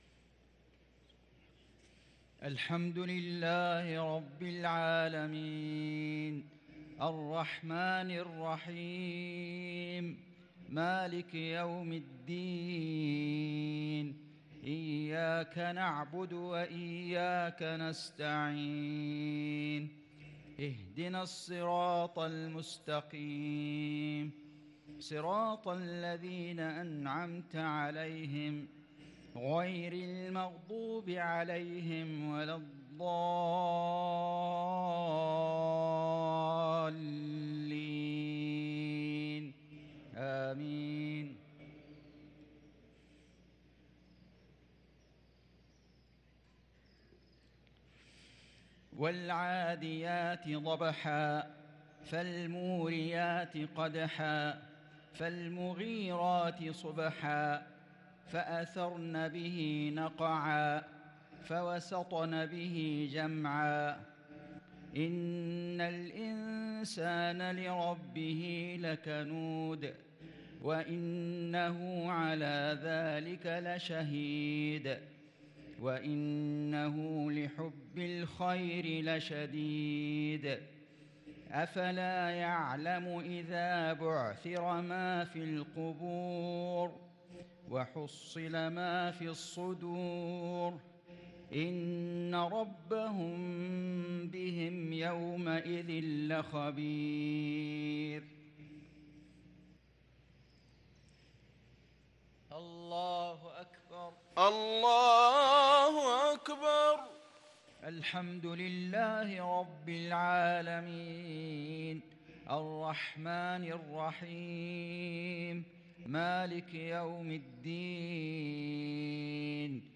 صلاة المغرب للقارئ فيصل غزاوي 17 رمضان 1443 هـ
تِلَاوَات الْحَرَمَيْن .